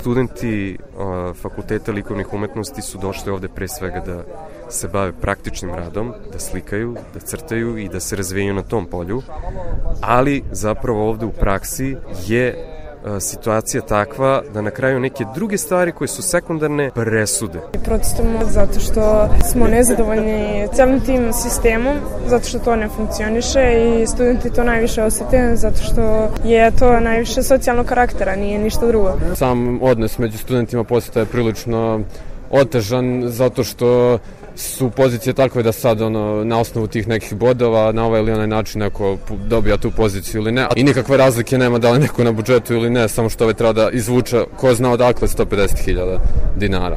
Studenti Fakulteta likovnih umetnosti o protestu